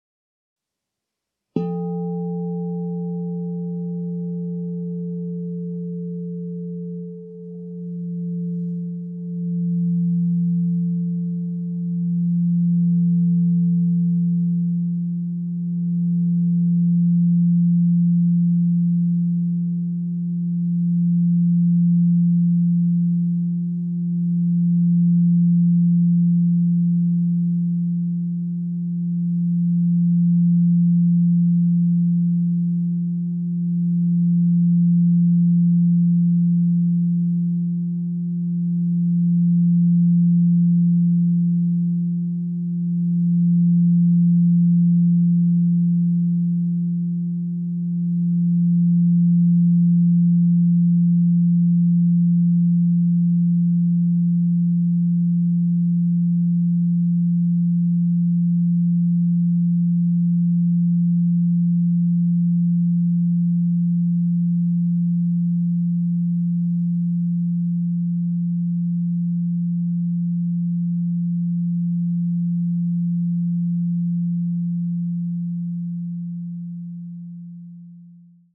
Meinl Sonic Energy 16" white-frosted Crystal Singing Bowl F3, 432 Hz, Herzchakra (CSB16F3)
Produktinformationen "Meinl Sonic Energy 16" white-frosted Crystal Singing Bowl F3, 432 Hz, Herzchakra (CSB16F3)" Die weiß-matten Meinl Sonic Energy Crystal Singing Bowls aus hochreinem Quarz schaffen durch ihren Klang und ihr Design eine sehr angenehme Atmosphäre.